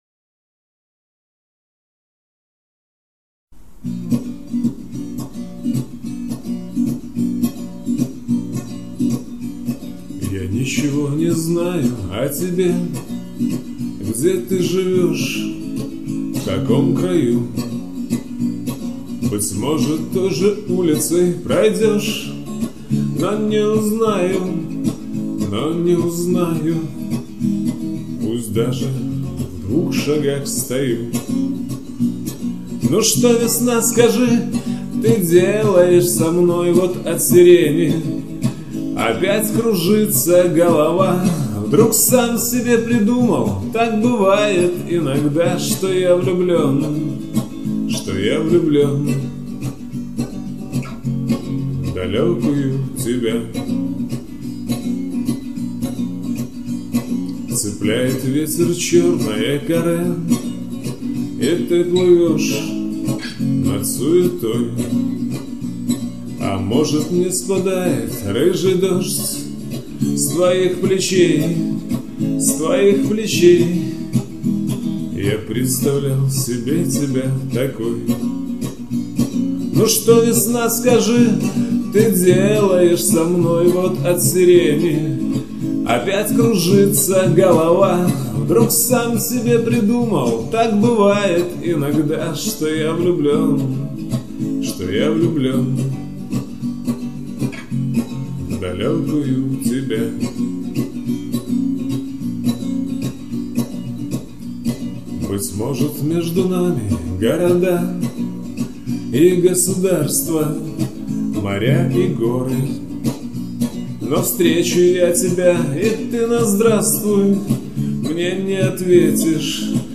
Лирические
Слушать на гитаре